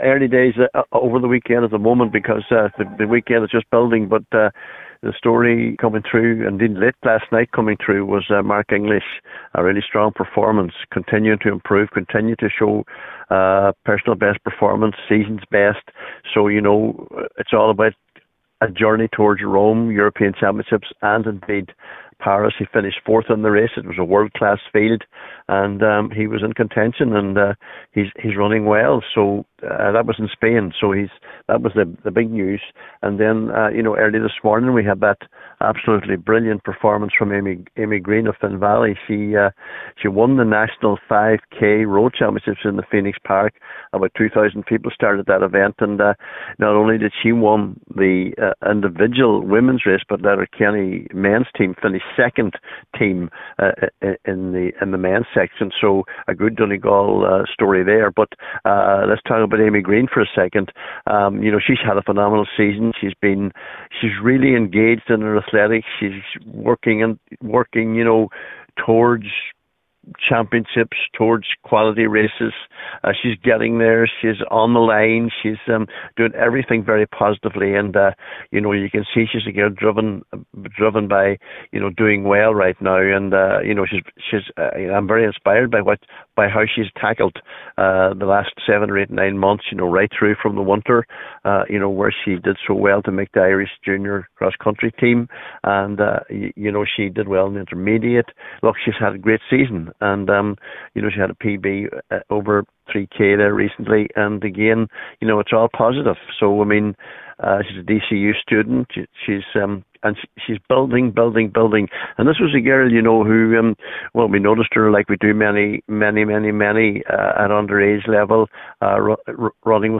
talking to Highland Radio’s